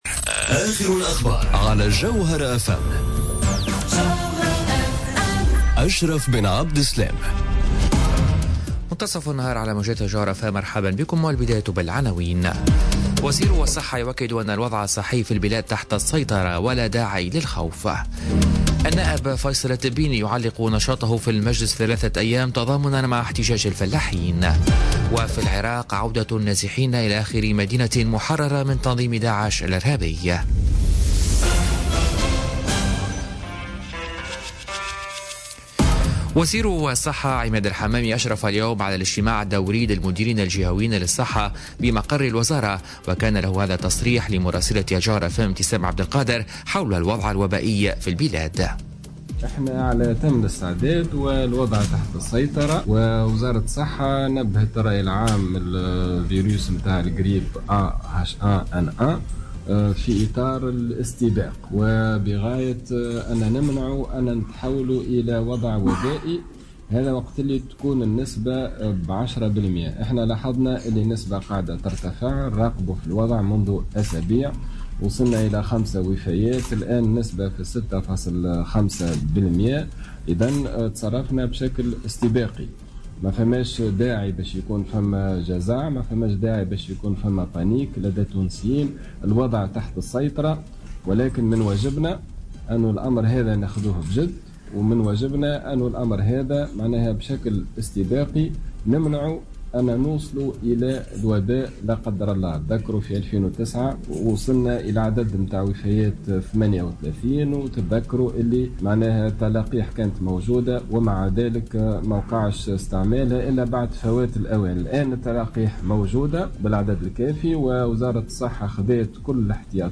نشرة أخبار منتصف النهار ليوم الثلاثاء 19 ديسمبر 2018